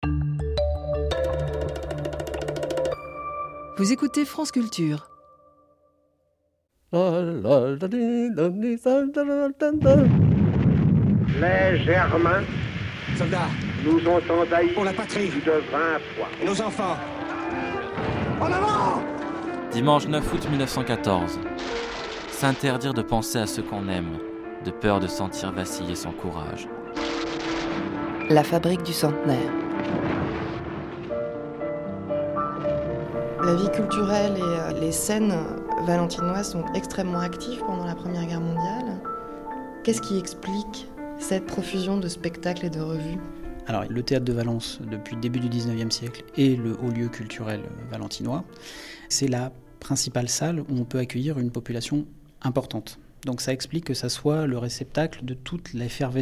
Interview radiophonique à Valence